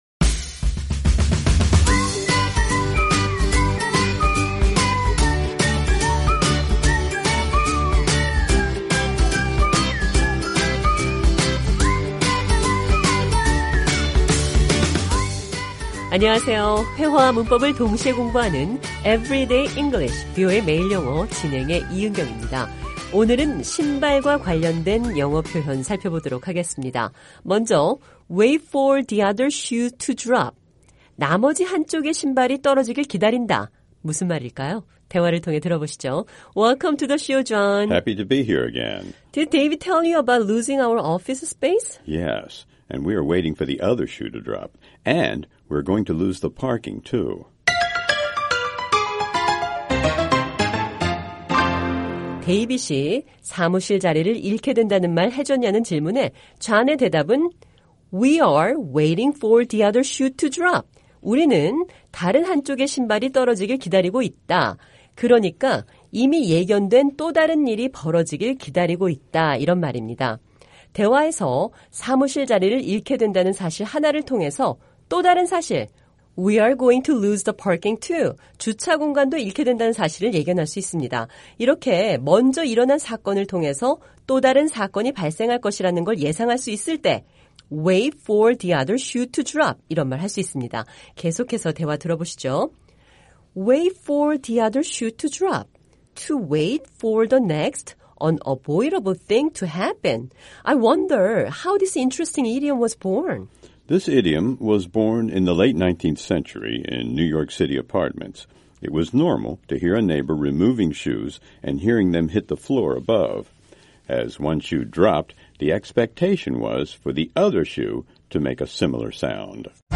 대화를 통해 들어보시죠.